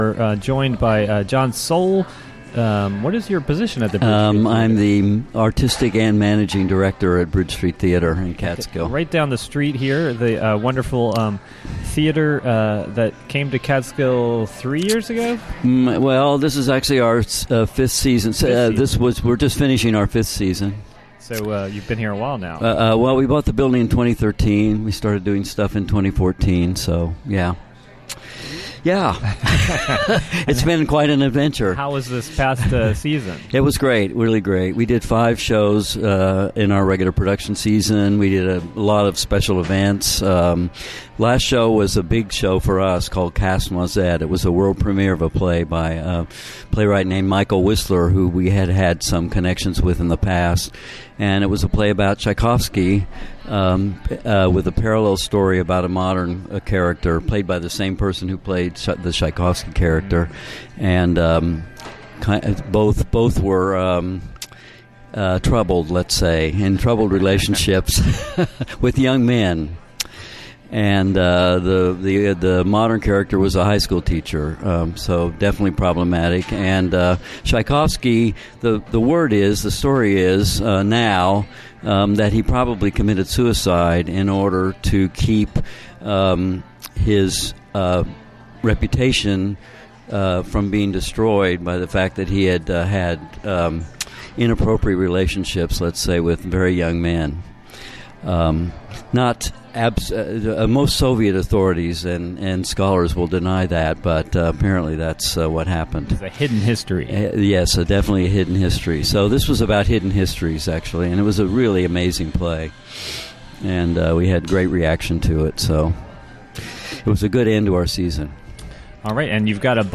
Interview live from HiLo in Catskill on the "WGXC Morning Show."